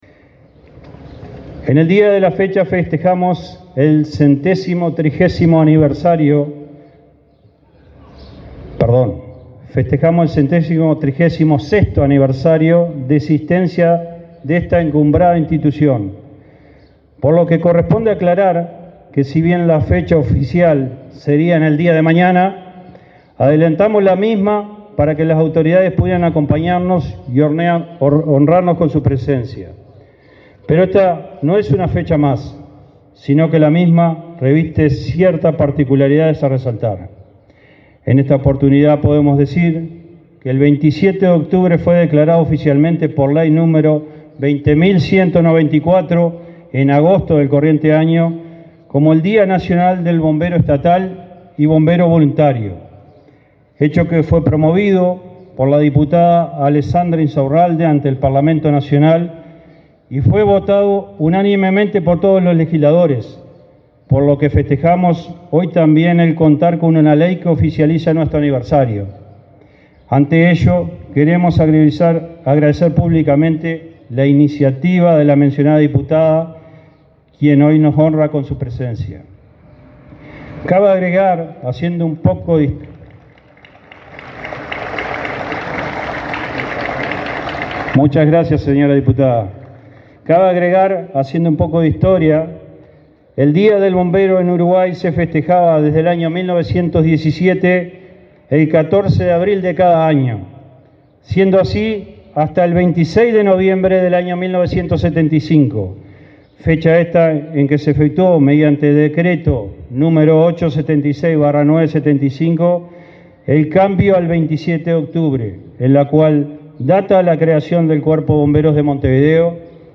Palabras de autoridades del Ministerio del Interior
Palabras de autoridades del Ministerio del Interior 26/10/2023 Compartir Facebook X Copiar enlace WhatsApp LinkedIn El director nacional de Bomberos, Ricardo Riaño, y el ministro Luis Alberto Heber, encabezaron la celebración del 136.° aniversario del Servicio de Bomberos, Día Nacional del Bombero y Bombero Voluntario, que se desarrolló este jueves 26 en el cuartel Centenario de Montevideo.